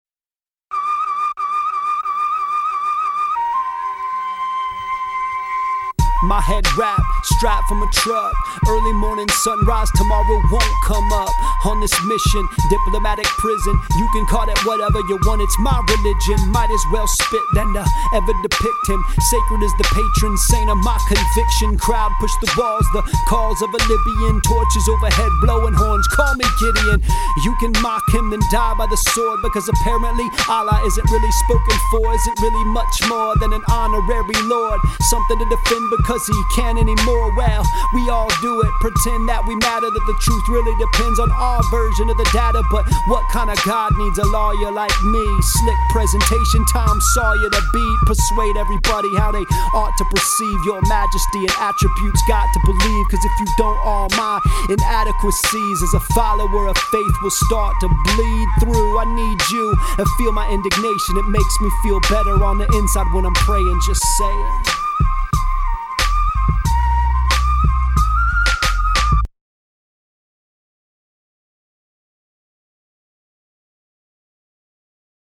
Today’s song blog here: